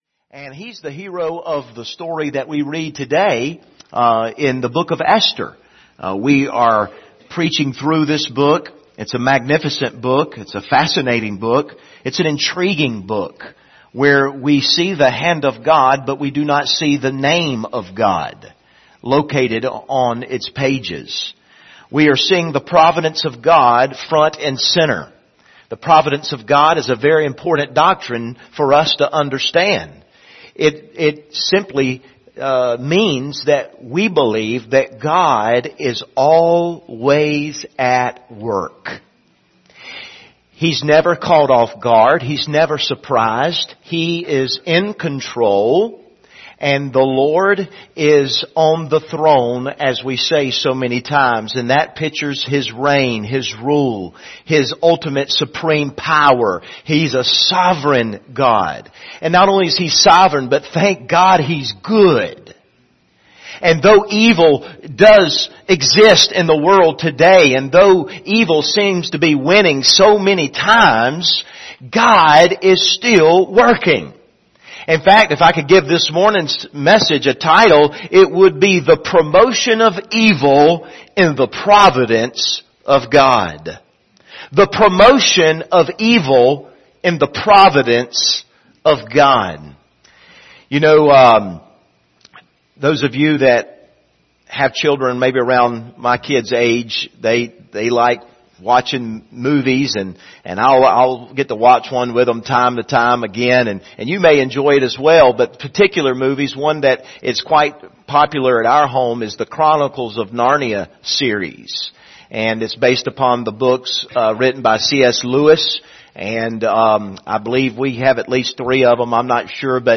Passage: Esther 3 Service Type: Sunday Morning